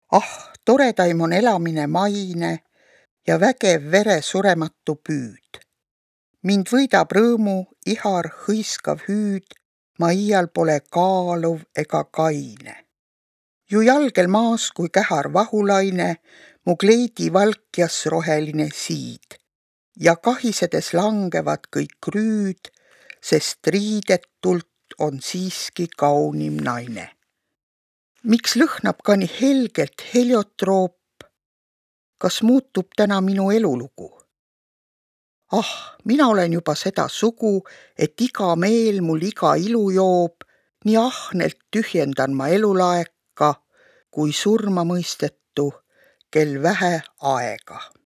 Kuulamis- ja kirjutamisharjutus 19